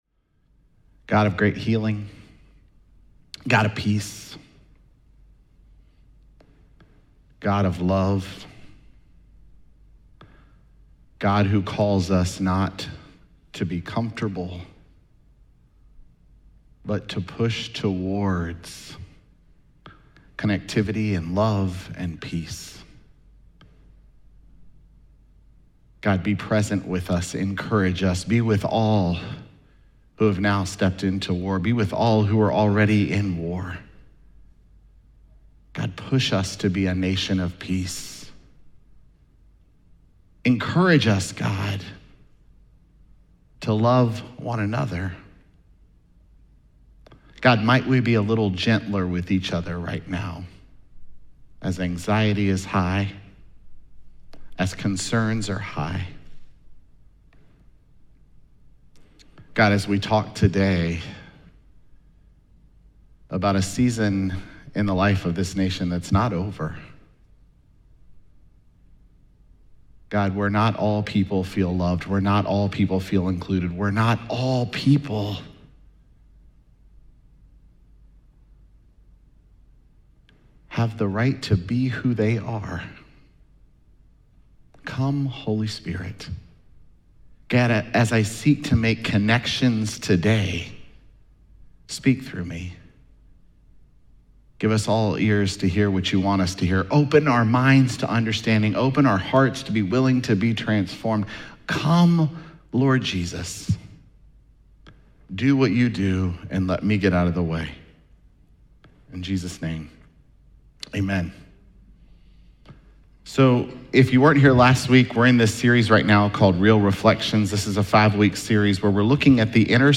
Jun22SermonPodcast.mp3